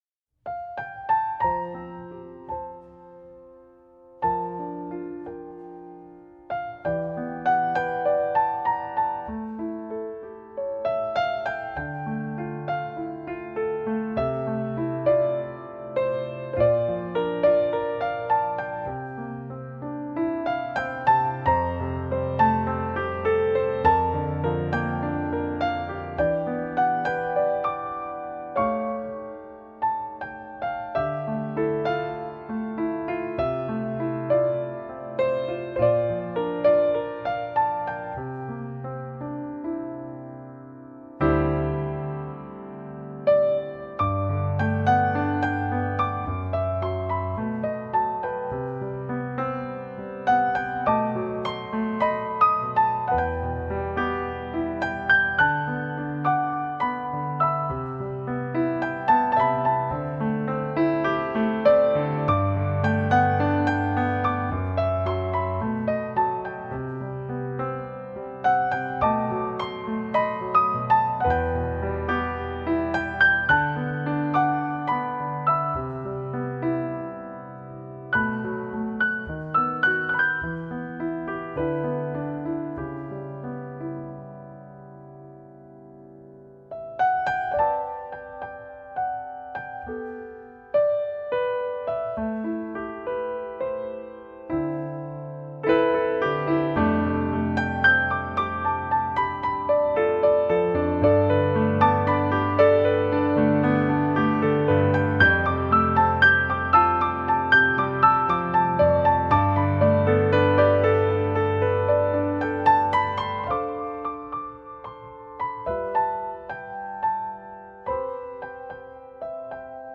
重回纯钢琴演奏本质的全新力作，
在延续以往的浪漫情韵外，更多了温暖人心的柔和色彩。